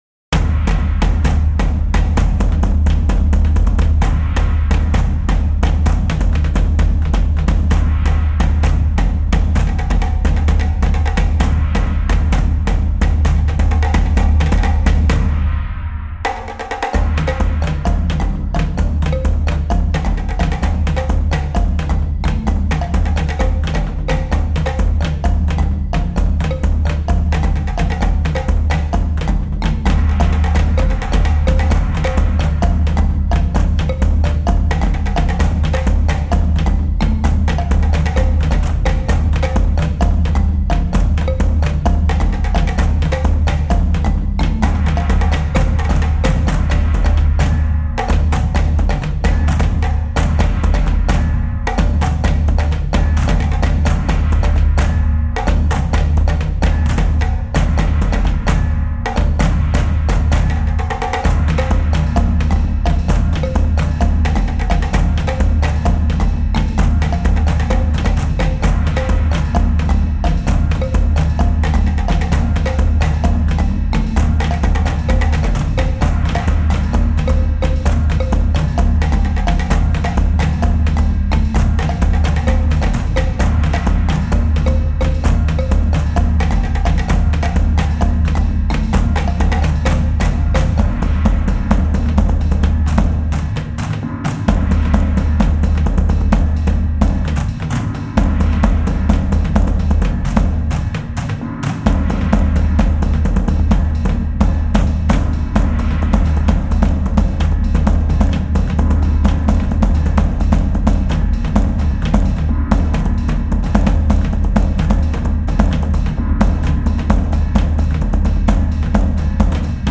和太鼓楽曲